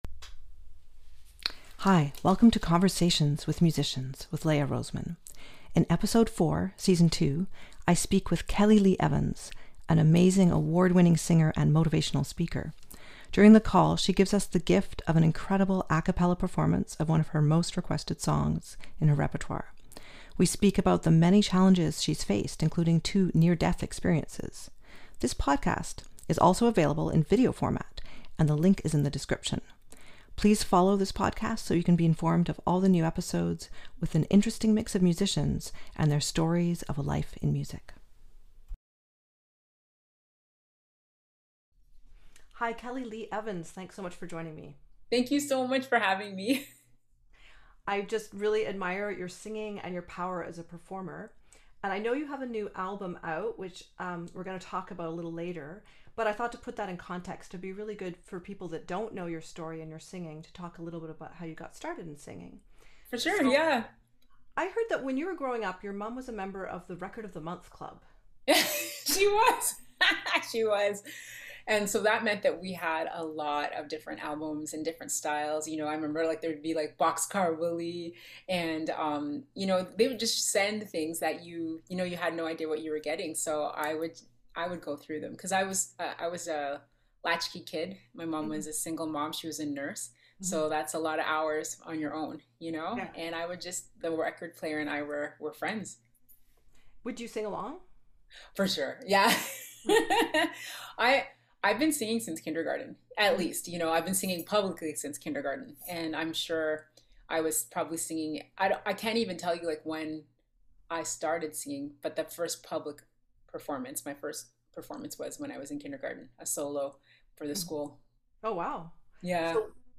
This interview series follows my curiosity about fellow musicians: how did they get to where they are today, what are their perspectives on a life in music?